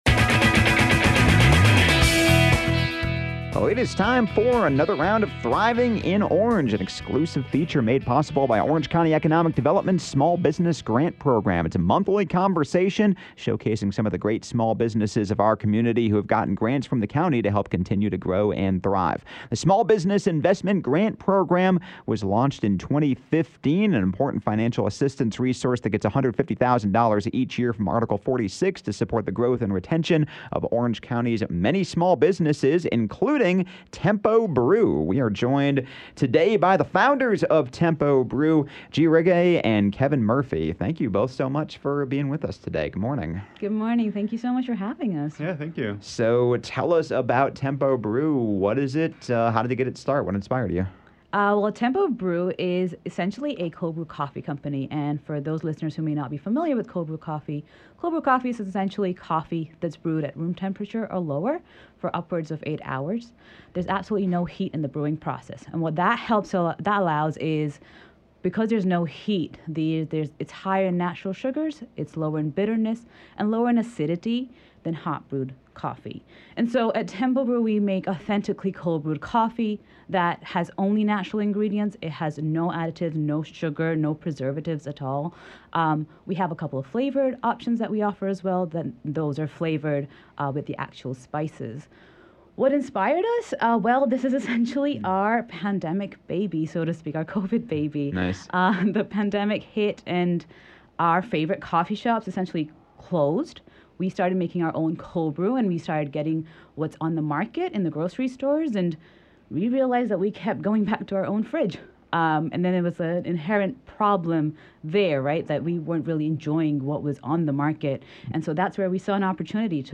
A monthly segment presented by Orange County Economic Development, “Thriving in Orange” features conversations with local business owners about what it’s like to live and work in Orange County, especially in light of the county’s small business grant program which launched in 2015 and has helped small businesses and small business owners with well over $100,000 in grants each year!